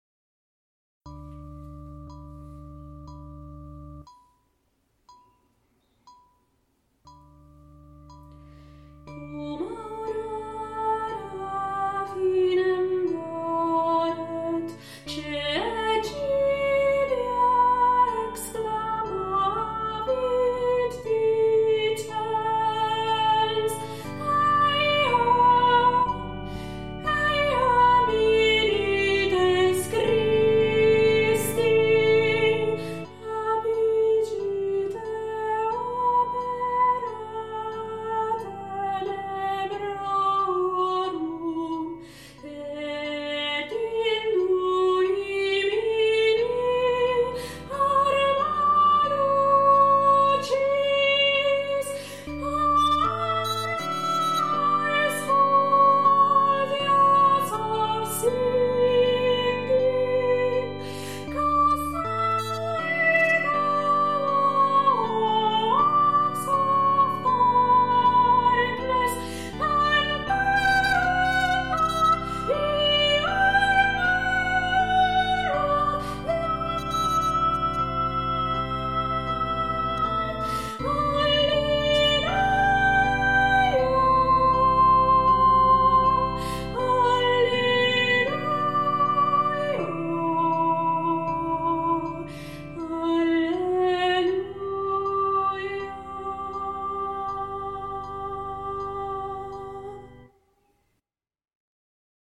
SOPRANO - Dum Aurora